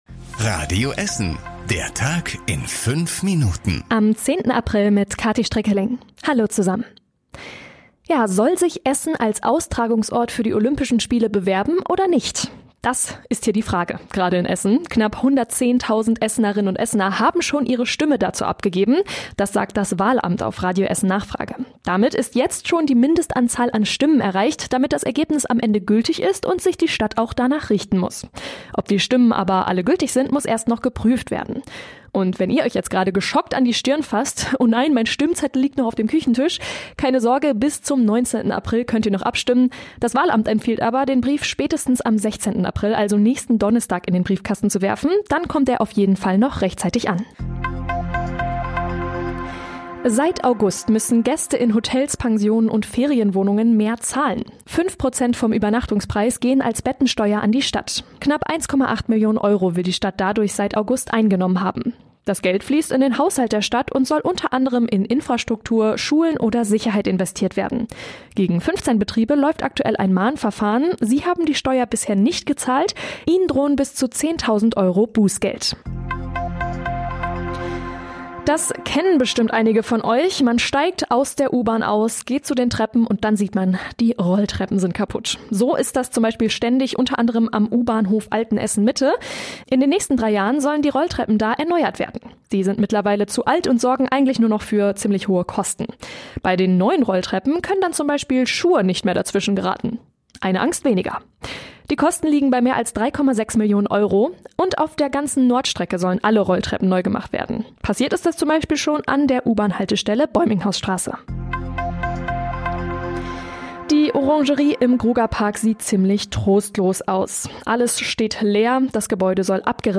Die wichtigsten Nachrichten des Tages in der Zusammenfassung
Nachrichten